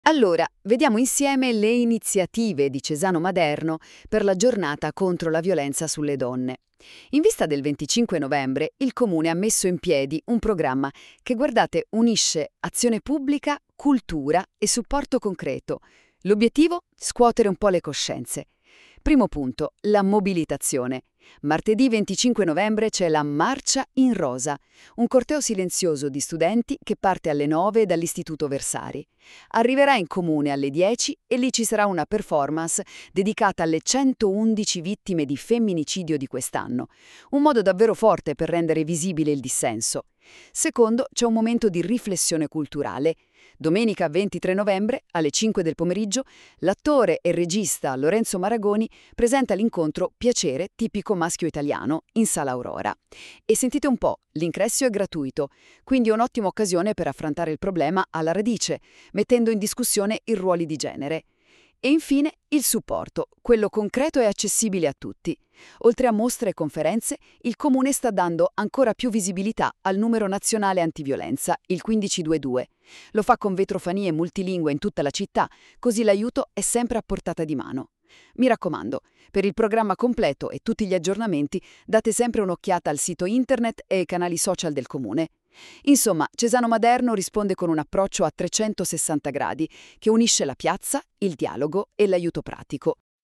Il podcast è stato realizzato con l’ausilio dell’IA, potrebbe contenere parziali errori nelle pronunce o in alcune definizioni.